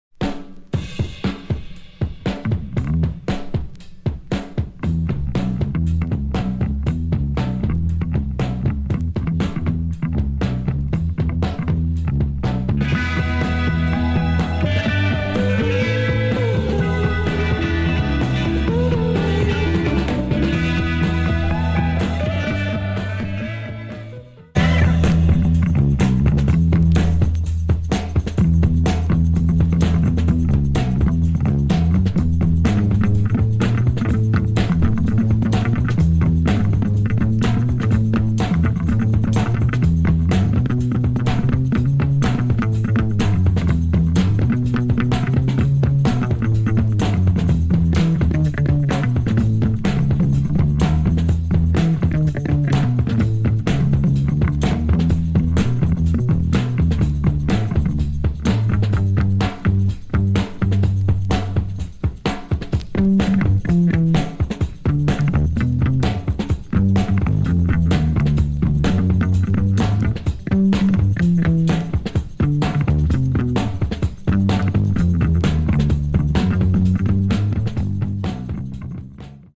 Polish group that plays pop rock and folk.